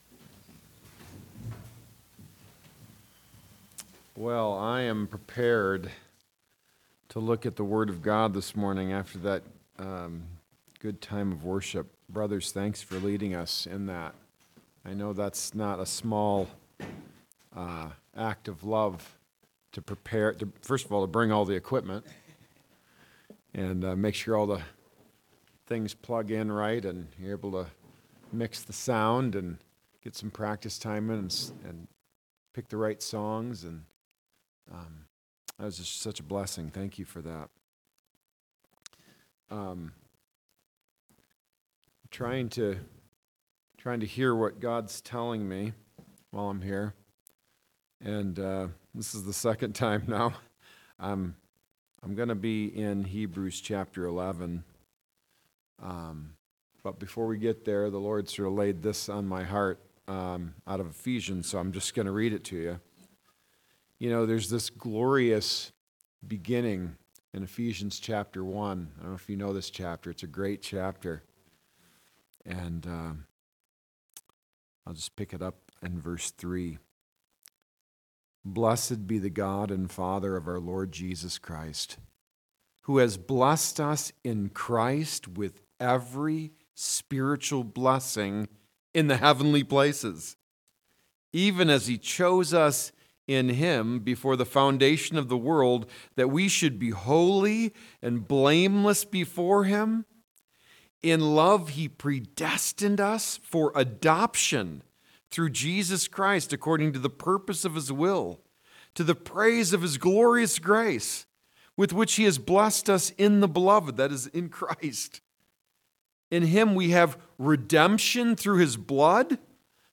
Men's Retreat Talks | The Landing Church